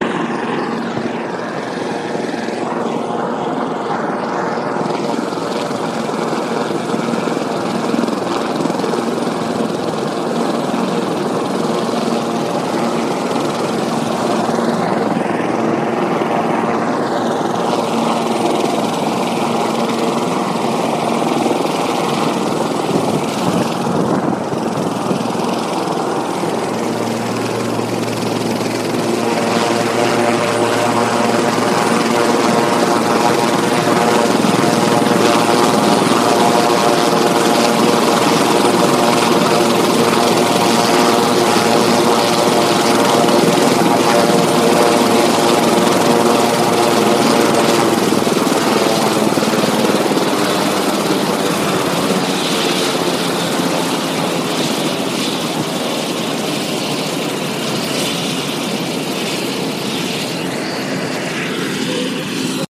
事情.火箭.01
描述：有人问到火箭爆炸的声音，我想象这可能类似于（至少是远程的）。
标签： 发动机 火箭
声道立体声